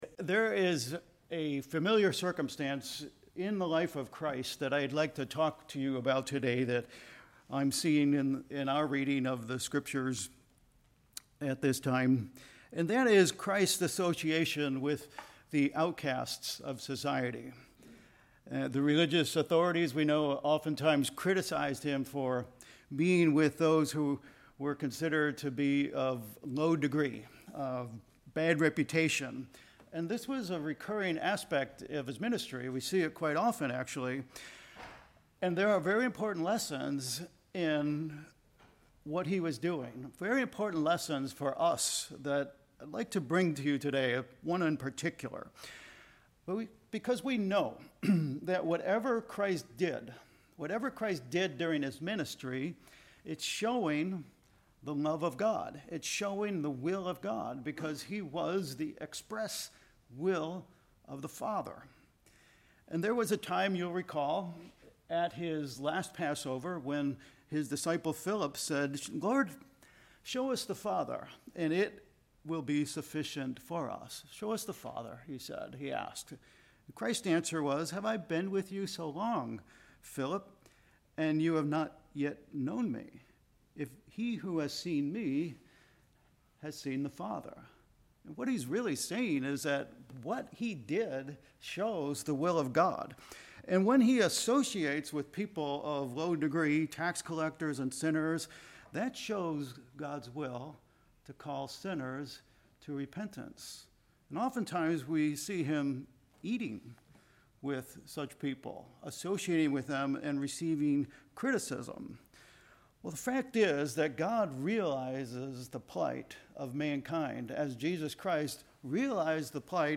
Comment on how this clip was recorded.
Given in Vero Beach, FL